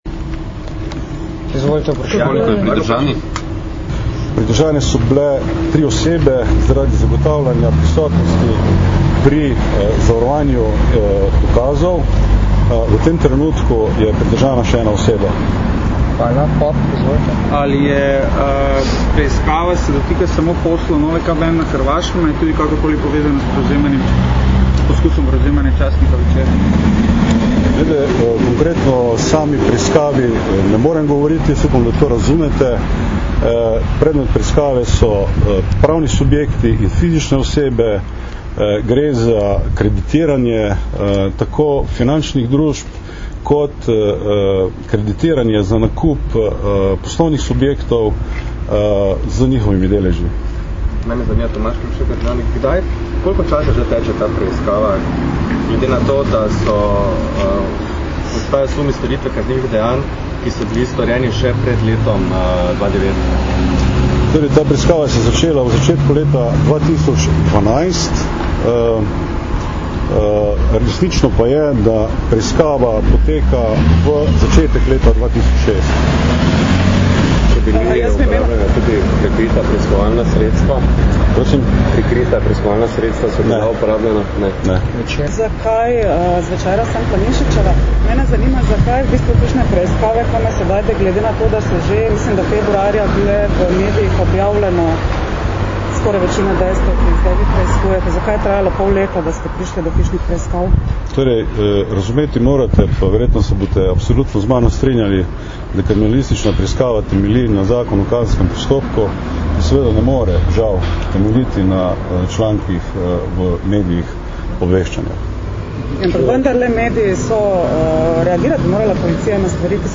Novinarska vprašanja in odgovori  (mp3)